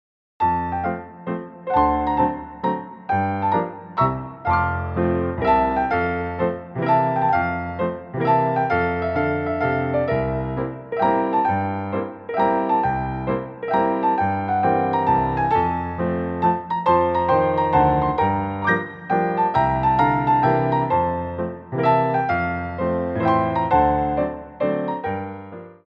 Pirouette 3 (En Dedans)
3/4 (8x8)